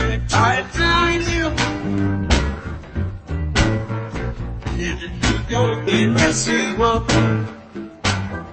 blues_blues.00001.mp3